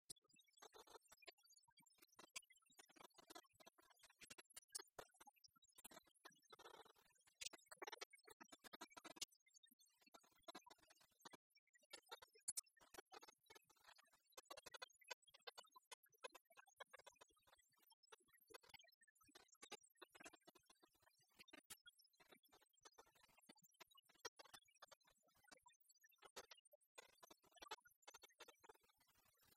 enfantine : berceuse
Genre strophique
Pièce musicale inédite